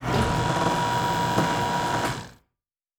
Sci-Fi Sounds / Mechanical
Servo Big 6_2.wav